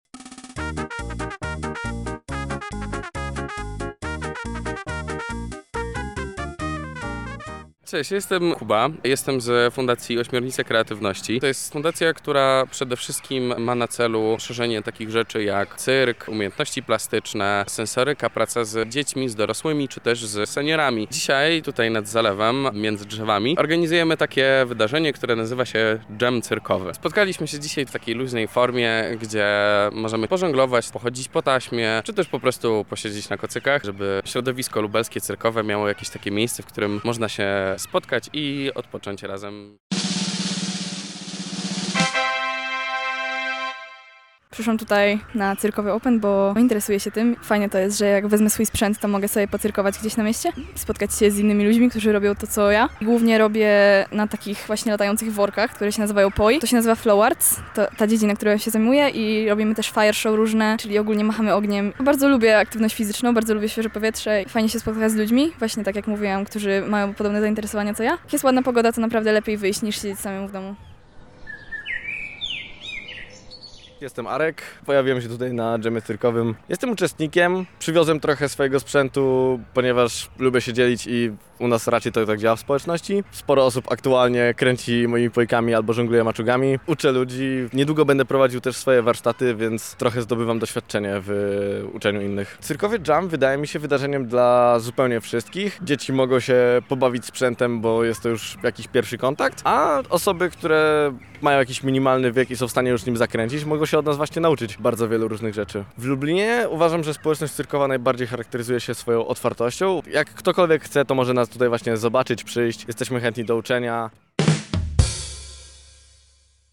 W niedzielę zebraliśmy się nad Zalewem Zemborzyckim na Cyrkowym Jamie.